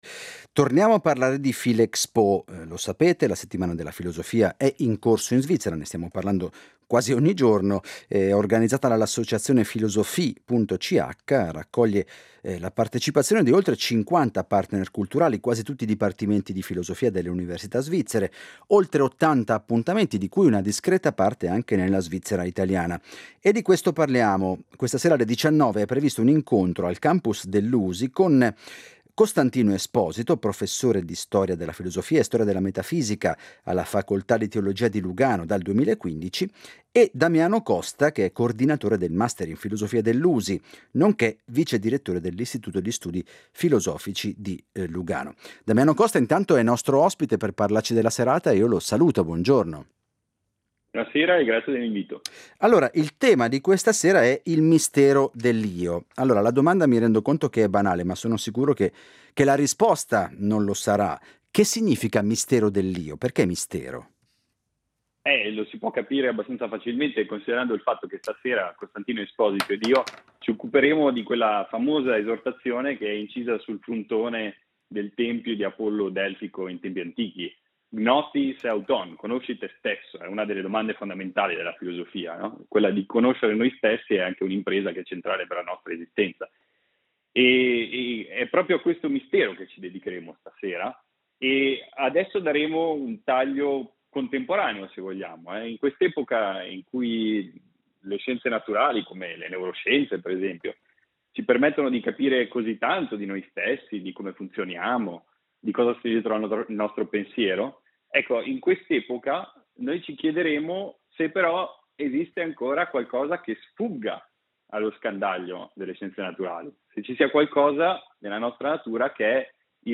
Ne parliamo con i relatori.